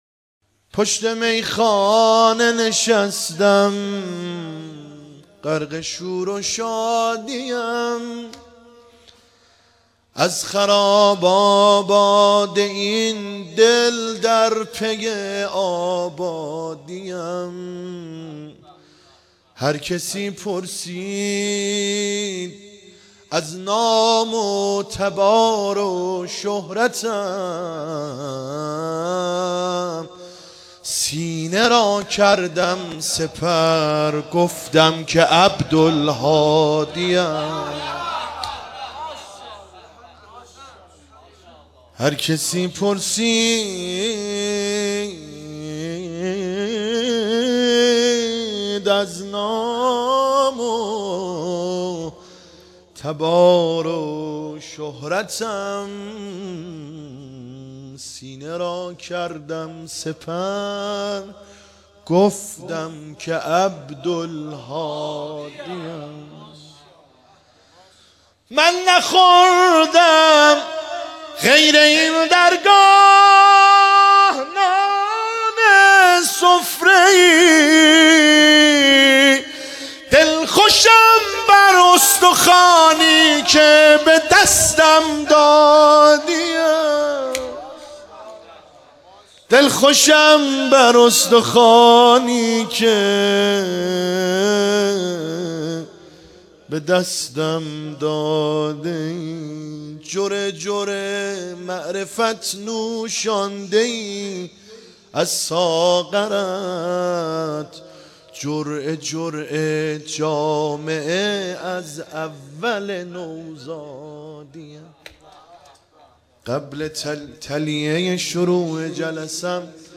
مولودی آذری